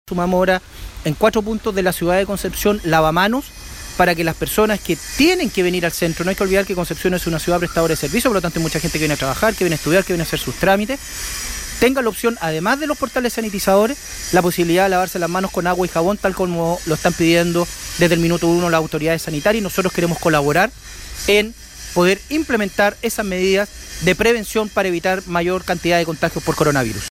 Junto con recordar que el principal llamado es a extremar los cuidados, el alcalde Álvaro Ortiz señaló los alcances de la medida.